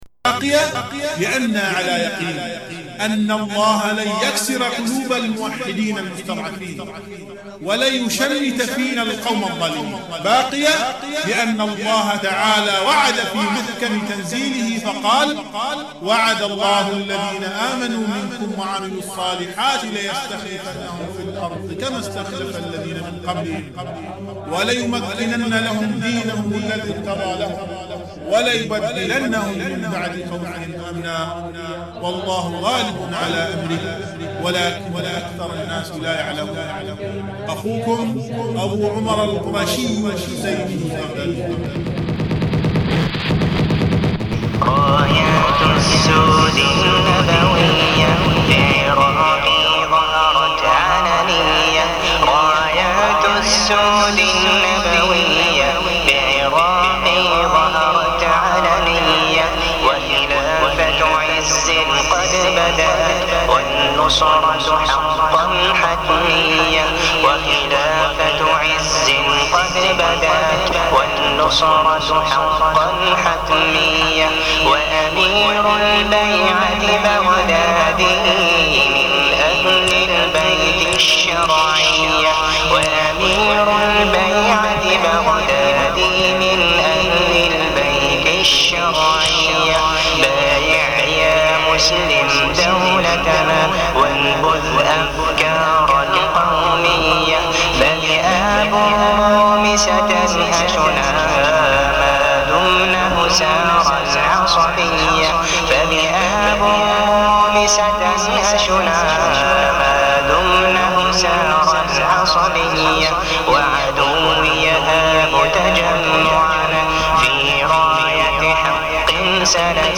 Раритетный нашид